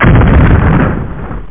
BigExplode.mp3